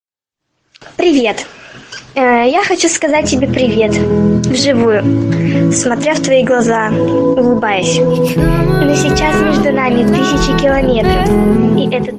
PLAY мррррр